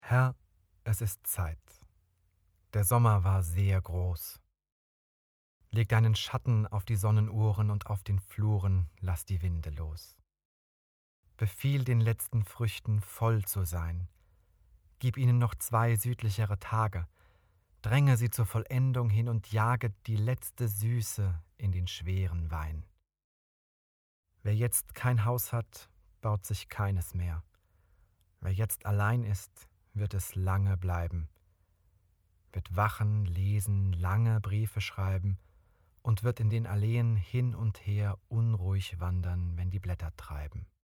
Sprecher & Moderator
Lyrik